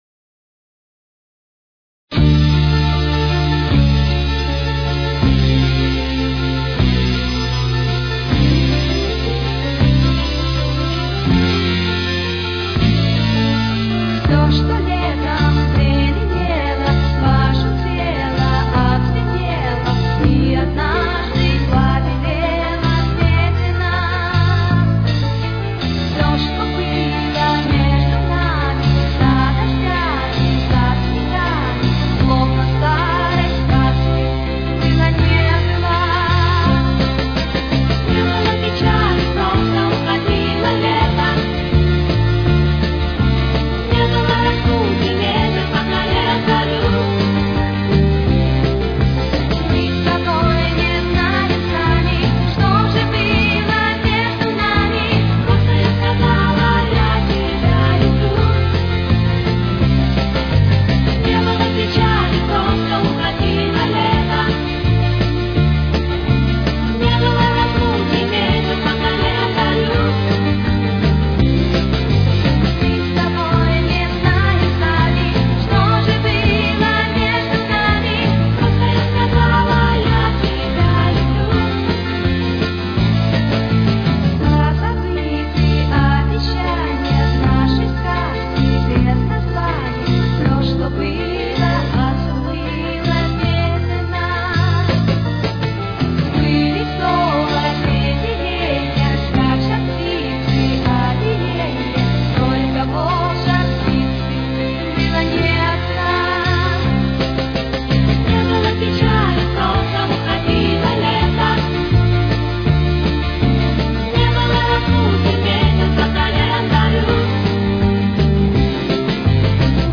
с очень низким качеством (16 – 32 кБит/с)
Тональность: Ре минор. Темп: 166.